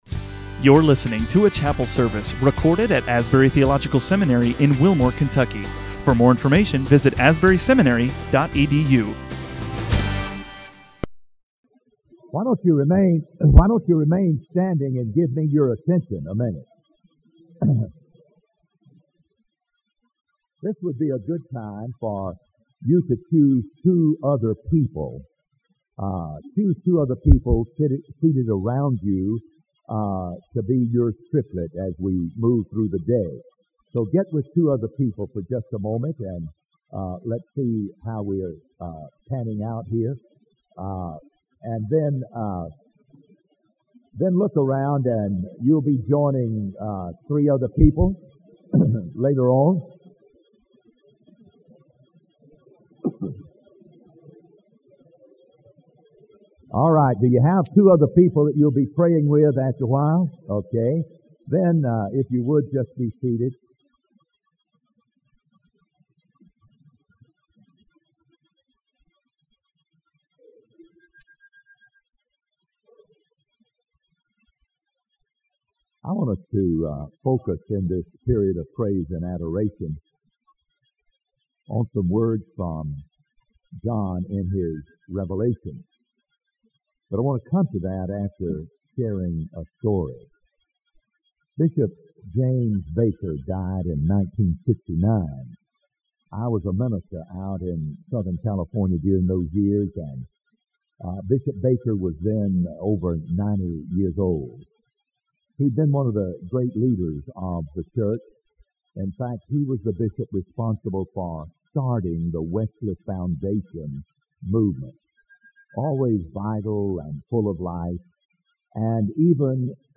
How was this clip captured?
Faculty chapel services, 1984